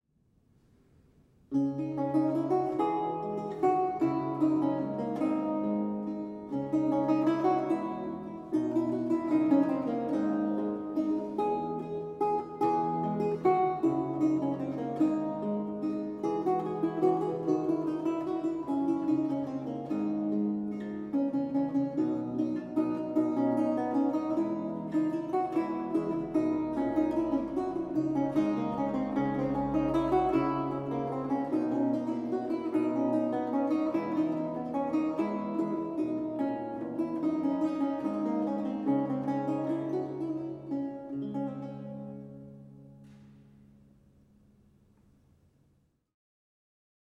a 16th century lute music piece
Audio recording of a lute piece from the E-LAUTE project